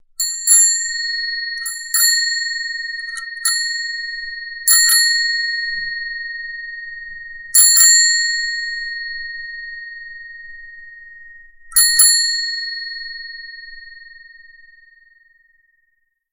Звуки велосипедного звонка
Звонкий гул велосипедного звонка